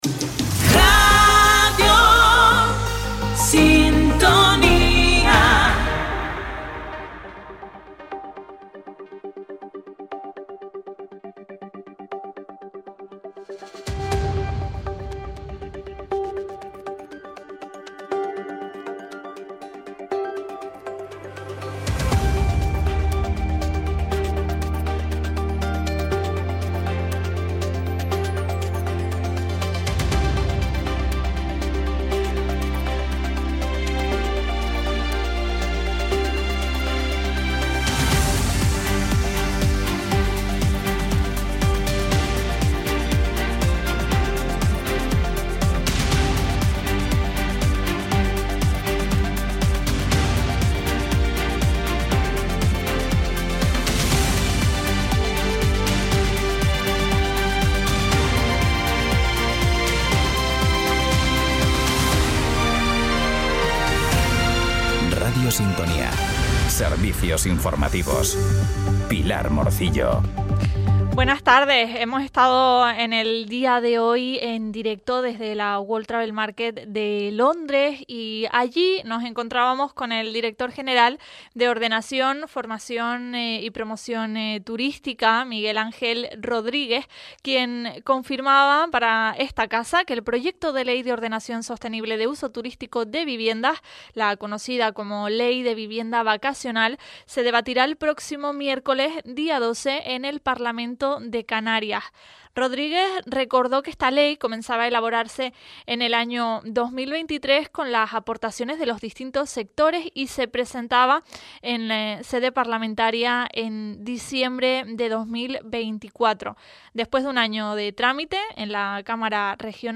En él te contamos, en directo, las noticias más importantes de la jornada, a partir de las 13:15h.